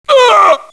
mil_pain2.wav